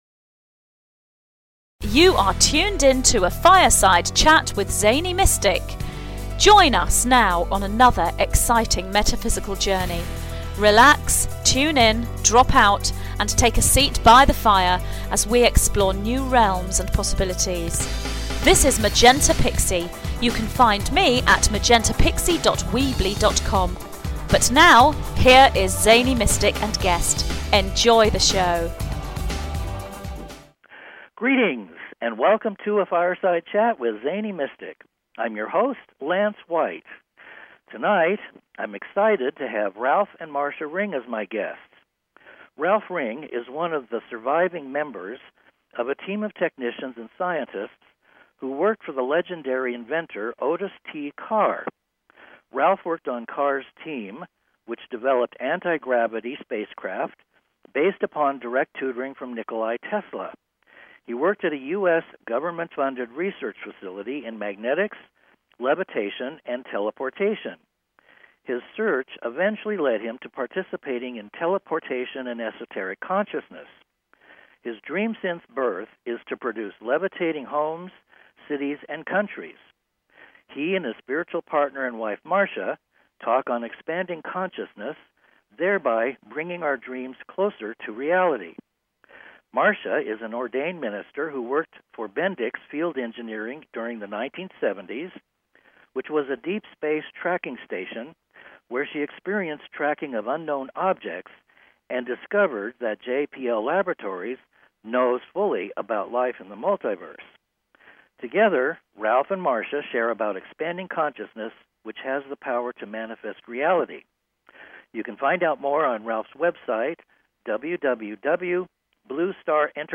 Guest Occupation Scientist, Technician, Anti-Grav, Free Energy, Tesla, Alternative future energy